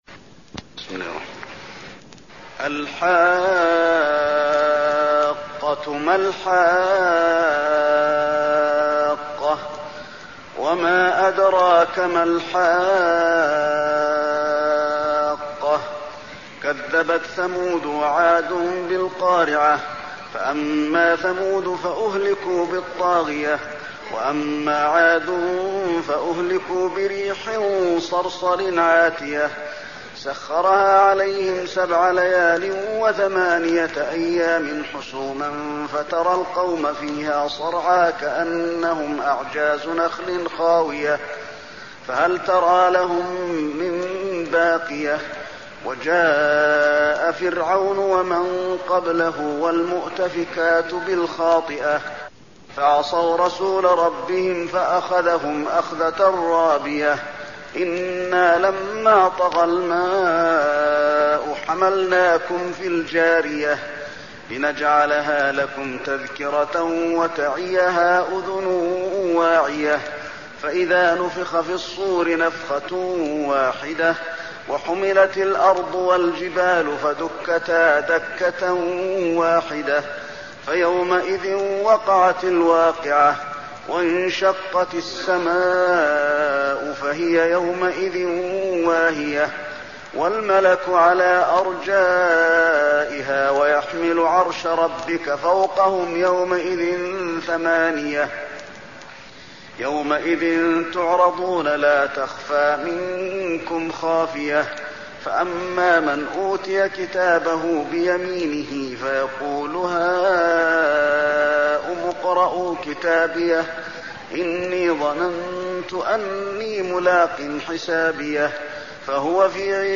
المكان: المسجد النبوي الحاقة